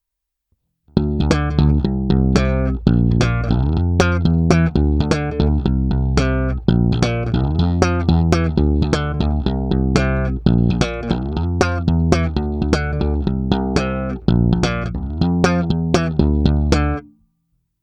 V tomto případě jsem dostal obě basy vybavené ocelovými hlazenými strunami.
Nahrávky jsou provedeny rovnou do zvukovky a dále kromě normalizace ponechány bez úprav.
3EQ 96 – Slap